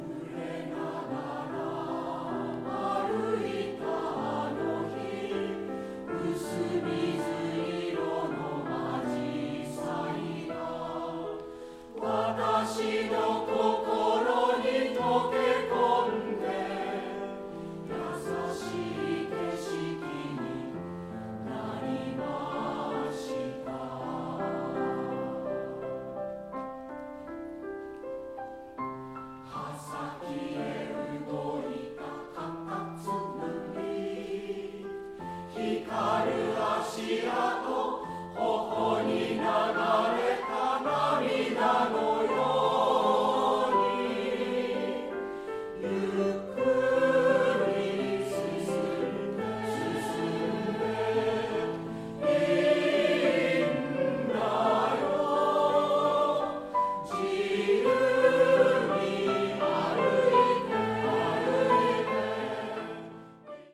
オリジナルコンサート　総評
(21)紫陽花を歌う合唱団(青年)